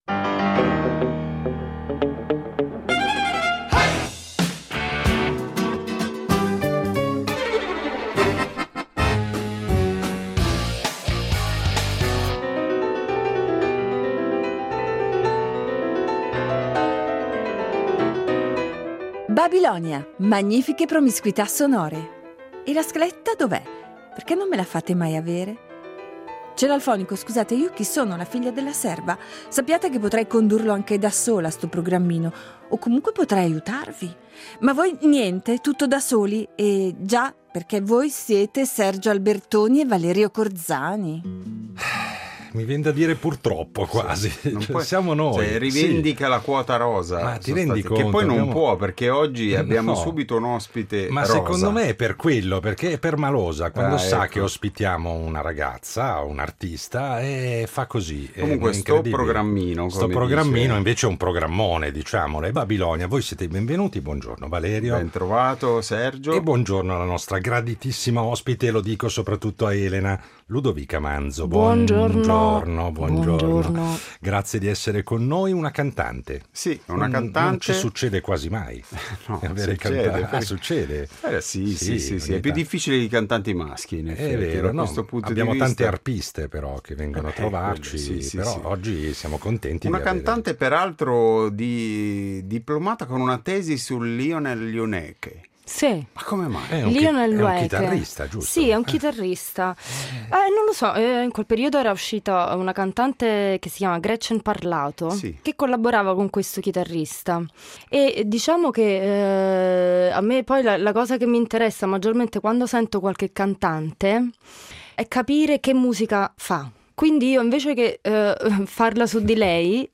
Cantante e compositrice, la nostra ospite di oggi è attiva come leader in progetti originali e come vocalist in diverse formazioni nell’ambito del jazz contemporaneo, della canzone d’autore e della musica improvvisata in senso lato.